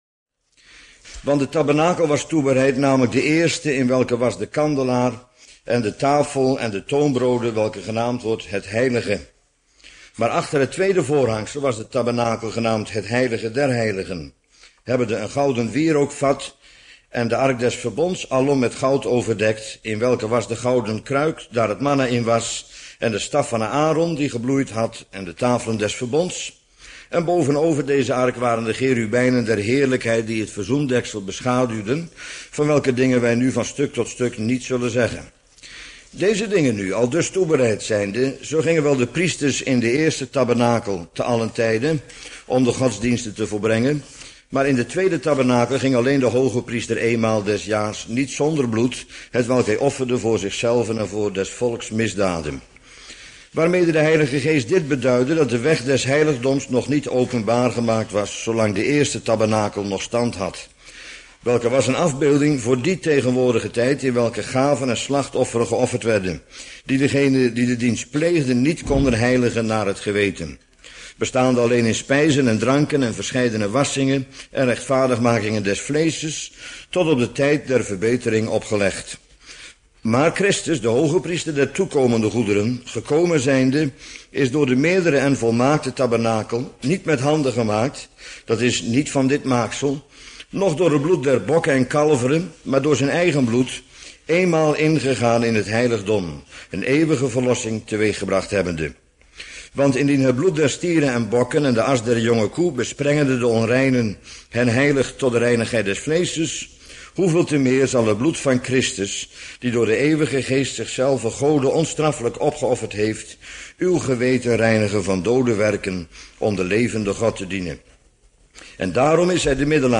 Bijbelstudie